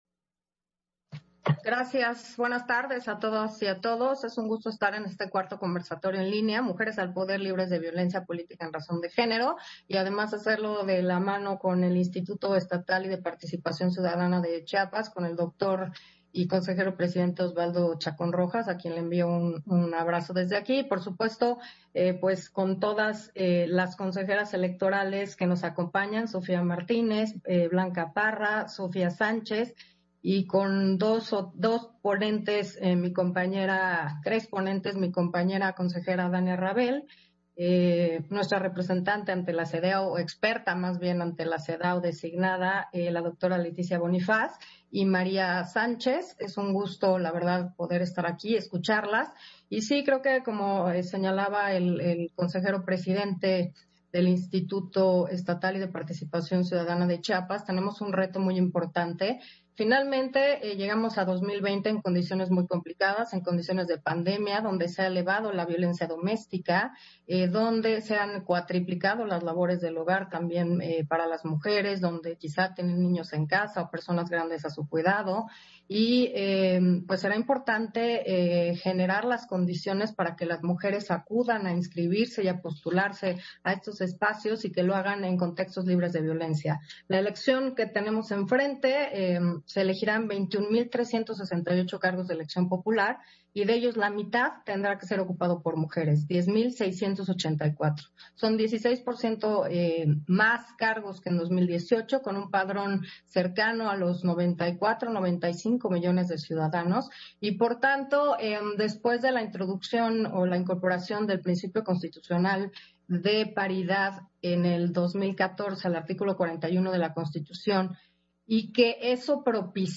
Intervención de Carla Humphrey, en el 4º Conversatorio en línea: Mujeres al poder libres de violencia política en razón de género